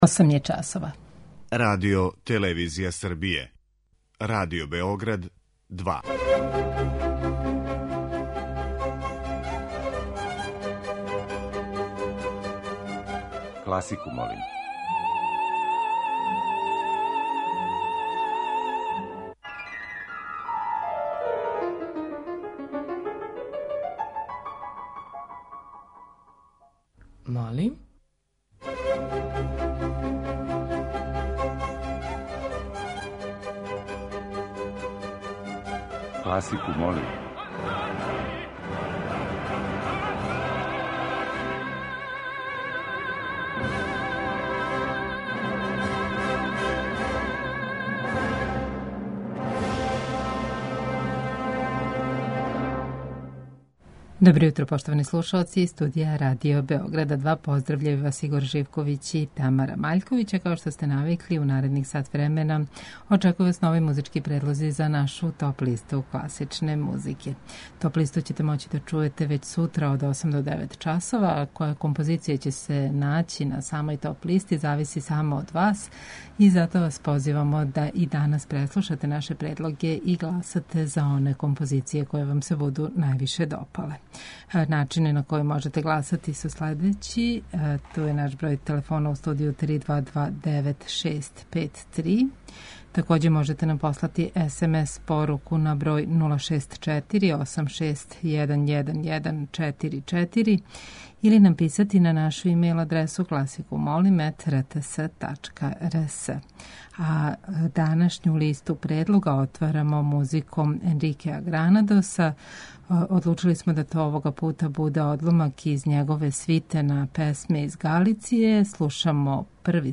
У оквиру теме овонедељног циклуса слушаћете одабране солистичке концерте за два или више инструмената.
Класику, молим, уживо вођена емисија, разноврсног садржаја, окренута је широком кругу љубитеља музике, а подједнако су заступљени сви музички стилови, епохе и жанрови.